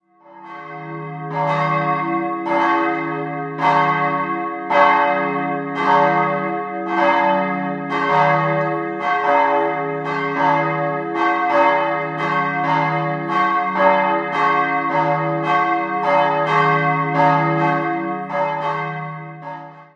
Erwähnenswert im Inneren sind die bunten Glasfenster. 2-stimmiges Große-Terz-Geläute: d'-fis' Die Glocken wurden 1906 vom Bochumer Verein gegossen und erklingen in den Tönen d'-3 und fis'-5.